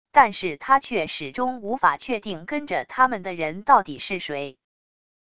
但是他却始终无法确定跟着他们的人到底是谁wav音频生成系统WAV Audio Player